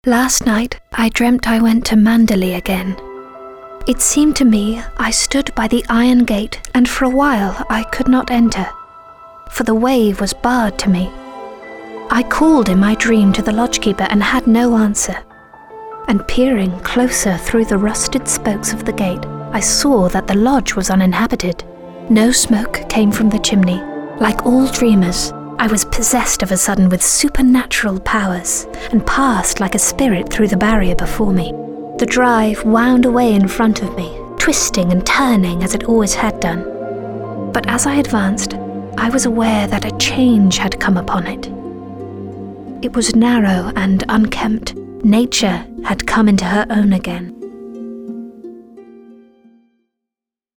Voice reels
• Fiction